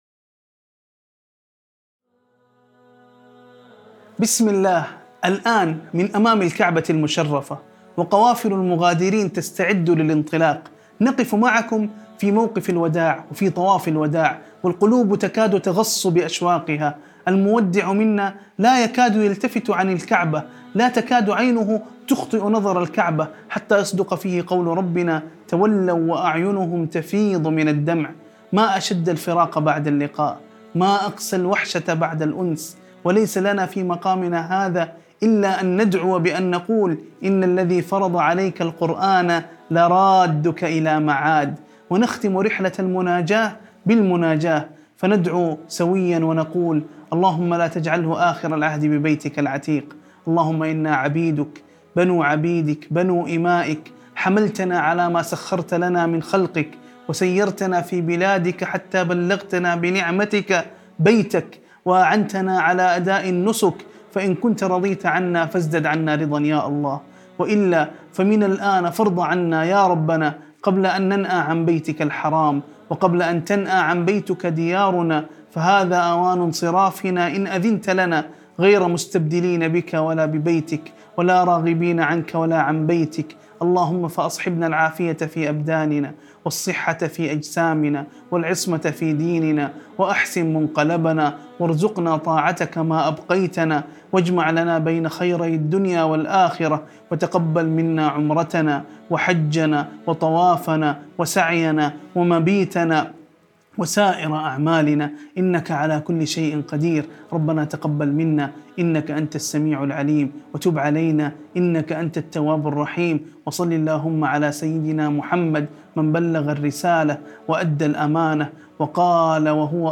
دعاء مؤثر يُناجي فيه القلبُ اللهَ تعالى عند وداع البيت الحرام، يعبر عن شوق اللقاء وألم الفراق، ويسأل الله القبول والعافية وحسن الخاتمة.